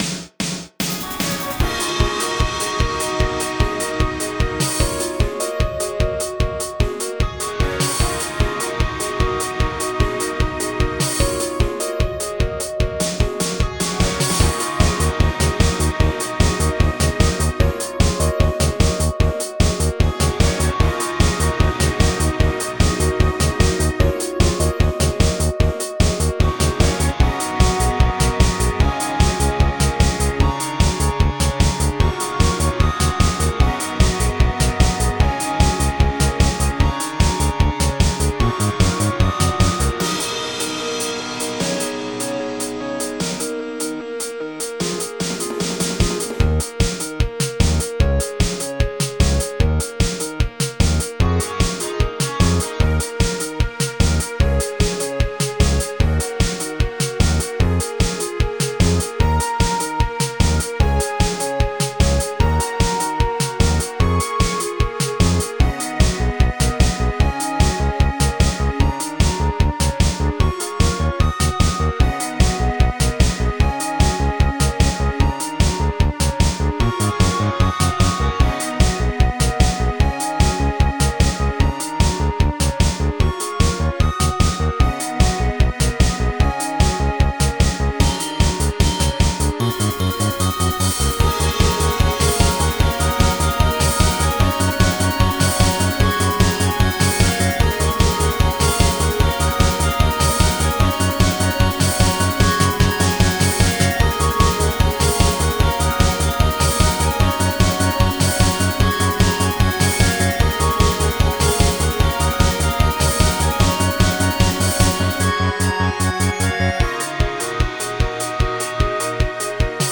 BGM
アップテンポインストゥルメンタルロング